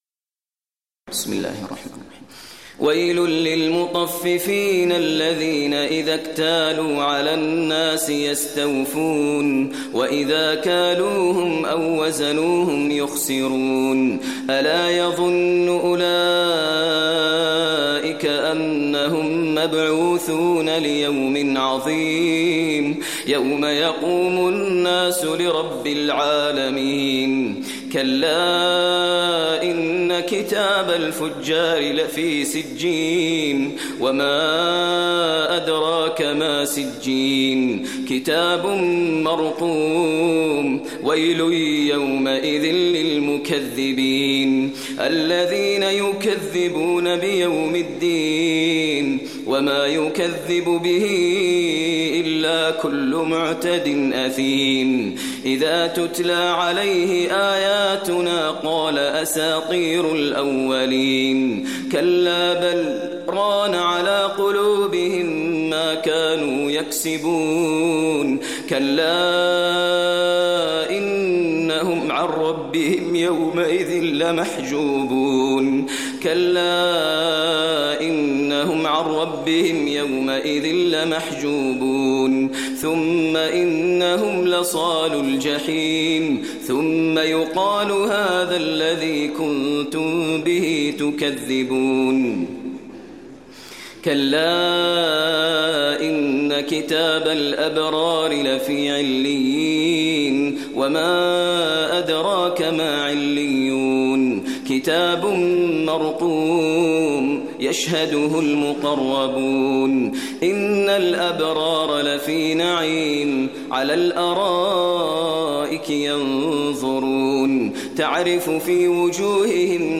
Surahs Mutaffifin Recitation by Maher al Mueaqly
Surah Mutaffifin, listen online mp3 tilawat / recitation in Arabic recited by Imam e Kaaba Sheikh Maher al Mueaqly.